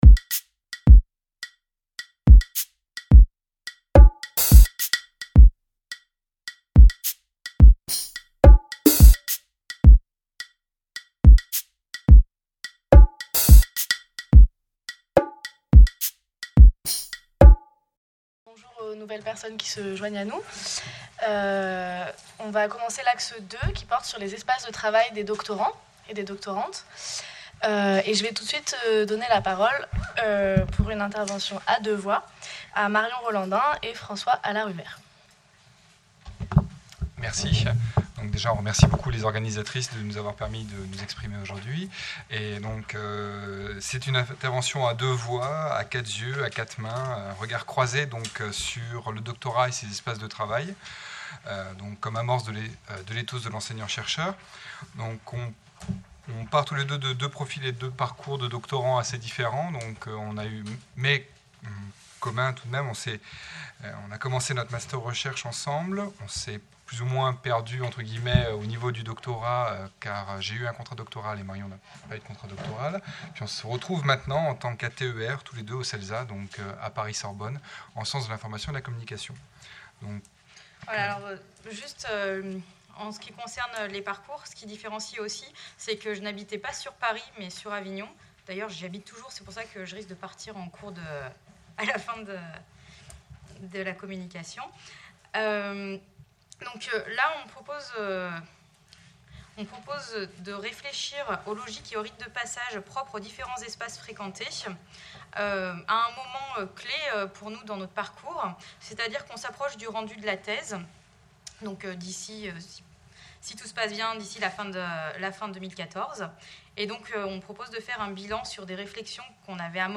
Le métier de doctorant.e en SHS Journée d'études organisée avec le soutien de l'Iris Enregistré le jeudi 25 septembre 2014, à l'EHESS, Salle du Conseil.